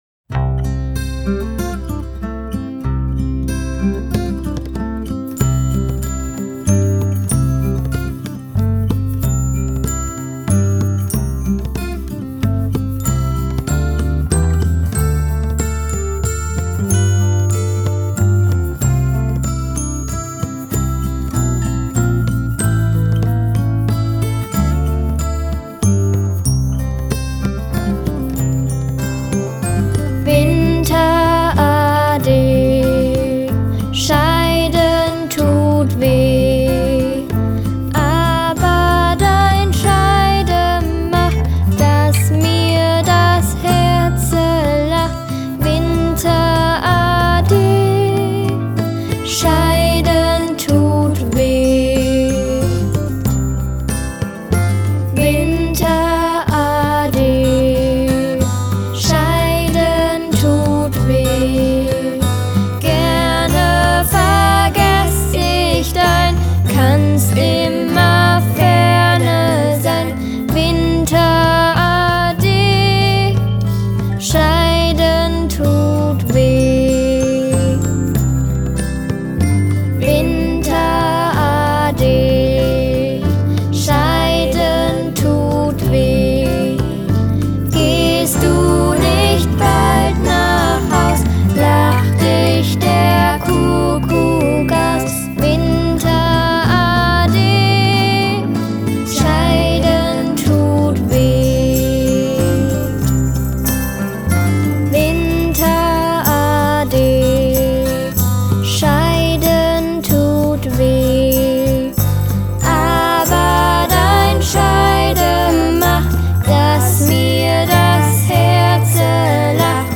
Jahreszeiten